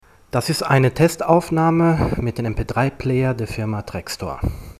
Trekstor: Hörprobe 2 (bearbietet: verstärkt)
trekstor-sprachtest2.mp3